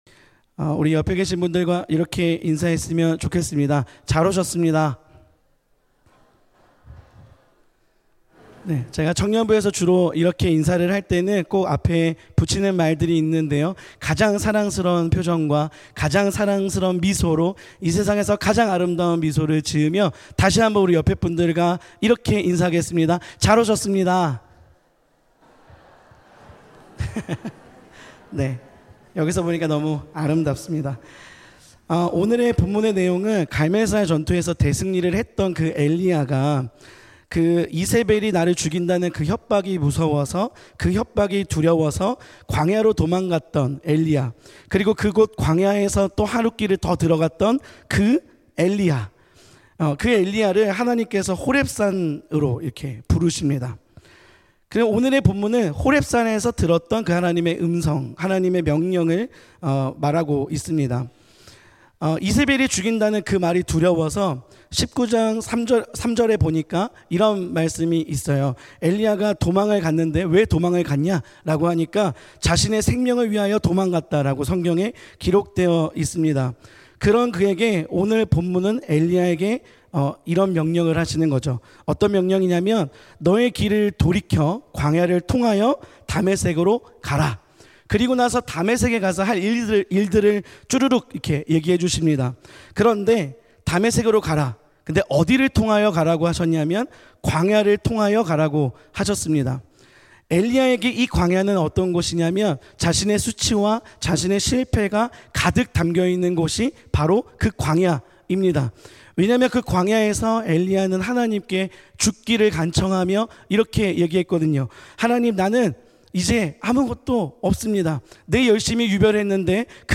금요성령기도회
나의 실패와 수치가 있는 곳으로 음성설교 듣기 MP3 다운로드 목록 이전 다음